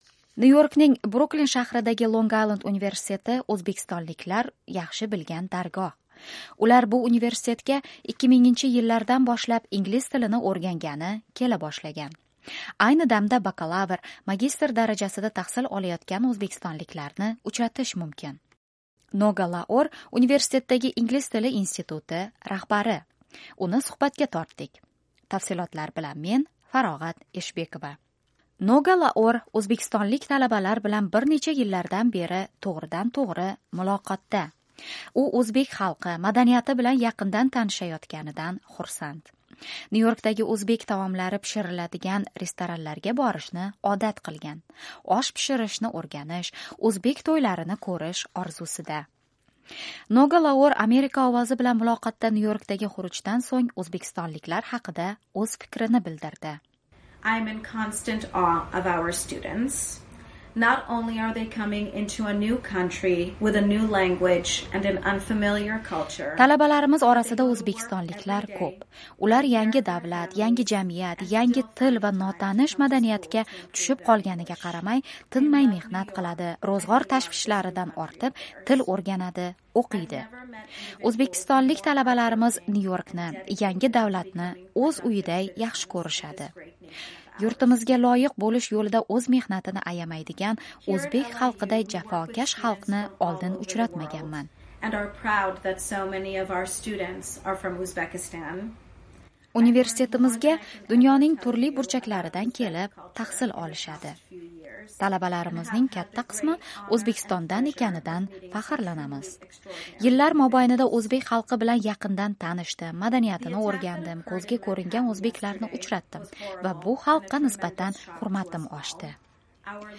"Amerika Ovozi" bilan video muloqotda quyidagilarni izhor etdi: